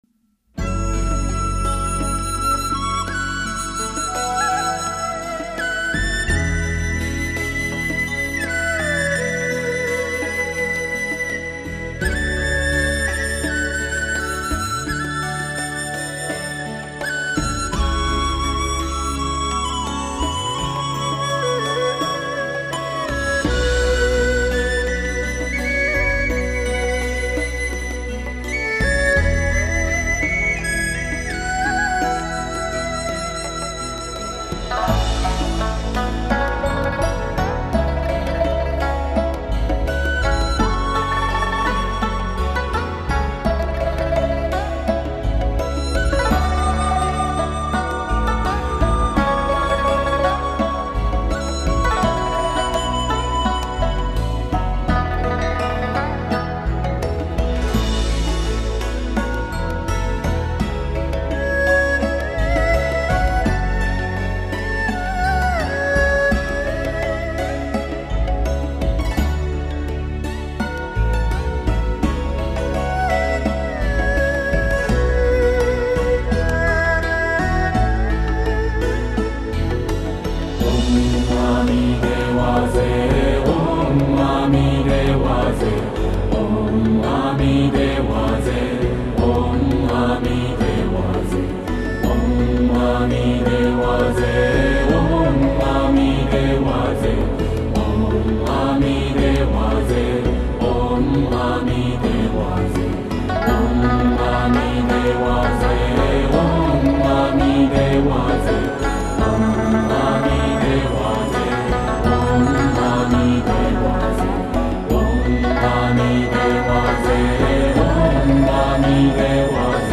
以流傳最廣的唱讚曲調為素材，結合流暢的鋼琴演奏